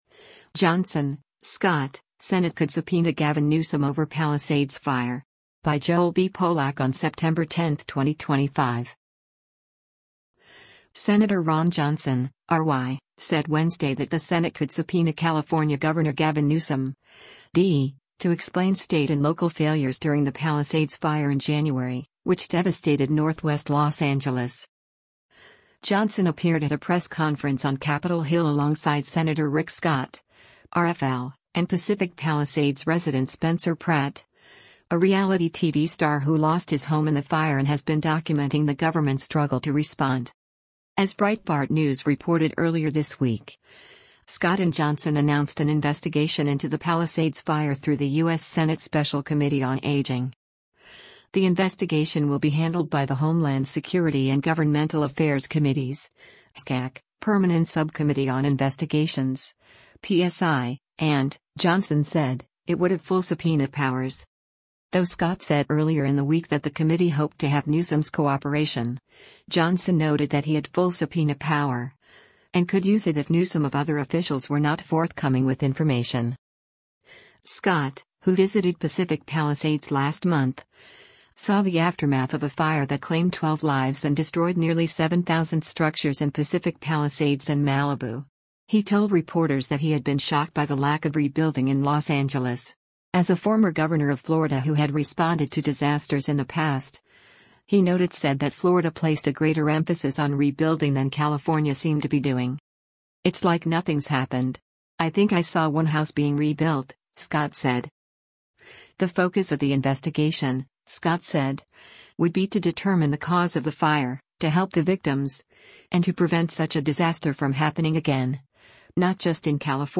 Johnson appeared at a press conference on Capitol Hill alongside Sen. Rick Scott (R-FL) and Pacific Palisades resident Spencer Pratt, a reality TV star who lost his home in the fire and has been documenting the government’s struggle to respond.